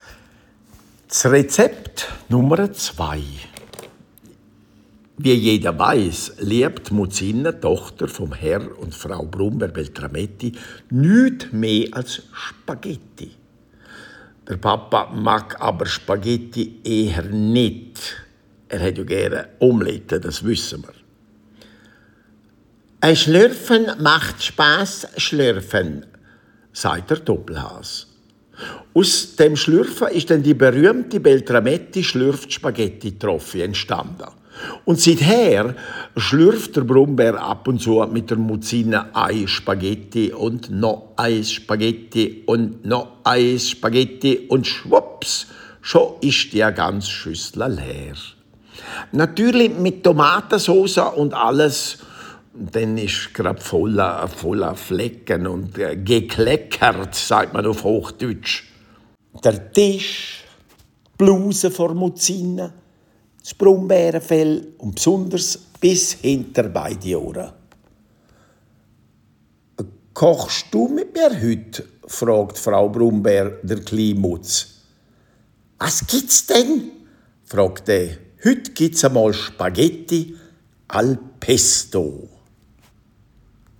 Alles auch als Hörgeschichten…
Gleichzeitig sind die Rezepte und Geschichten auch als Hörgeschichten via QR-Code im Buch erhältlich; auf Schweizerdeutsch/Dialekt sowie auch auf Hochdeutsch – erzählt und gesprochen von Linard Bardill.
Hörbeispiel zum Rezept Nr. 2 «Al pesto» (Dialekt-Version) >>>